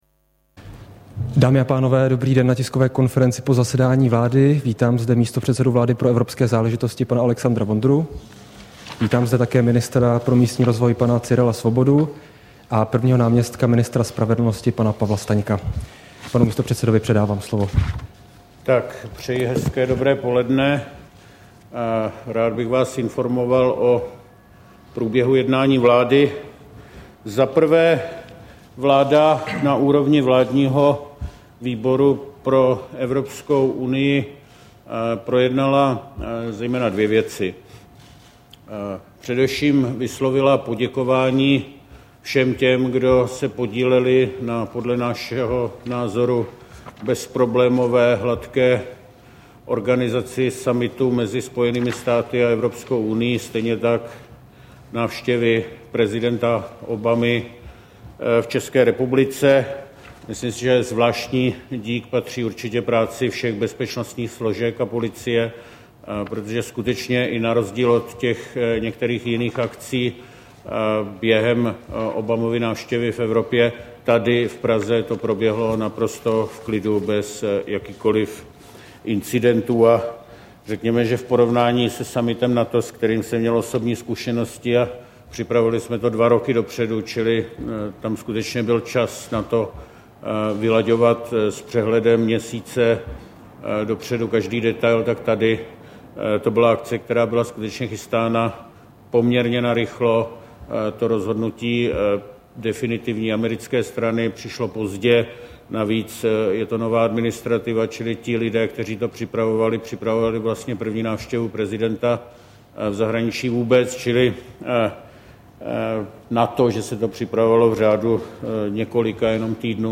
Tisková konference po jednání vlády 6. dubna 2009